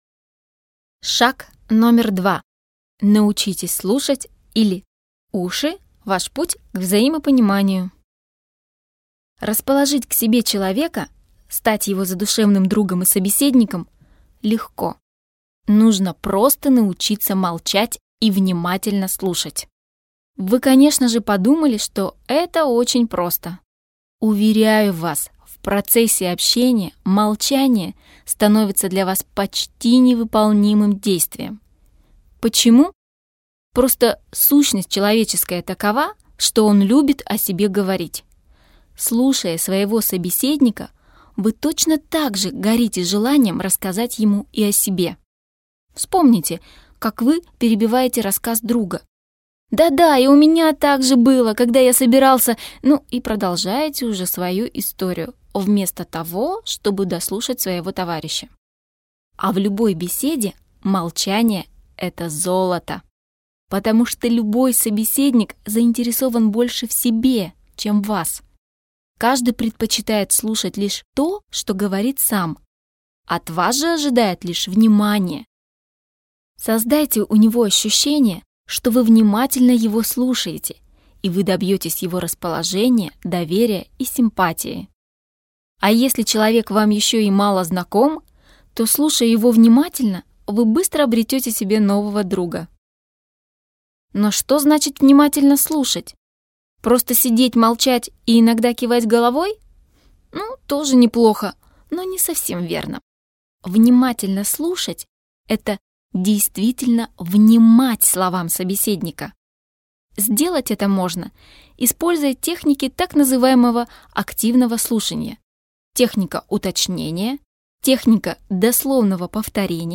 Аудиокнига Камасутра общения. Магия слов и жестов | Библиотека аудиокниг